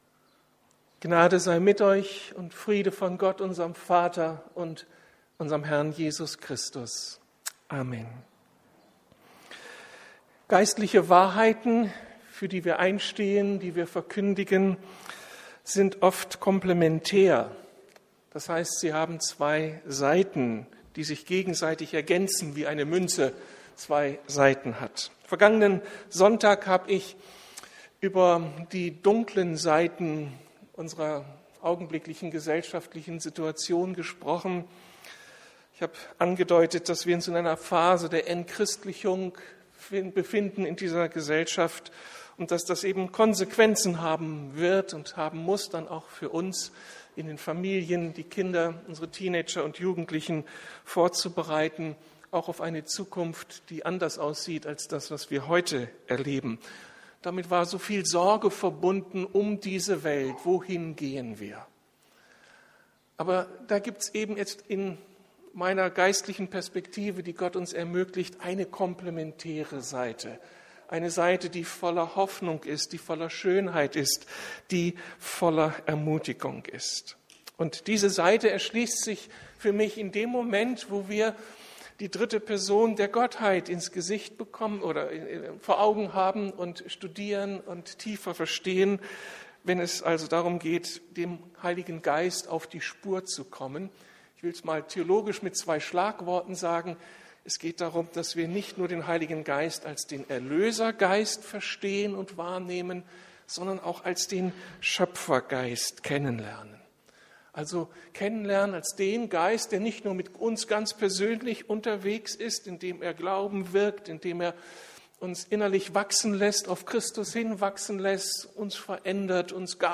Eine Entdeckungsreise der besonderen Art- der Geist Gottes in der Schöpfung ~ Predigten der LUKAS GEMEINDE Podcast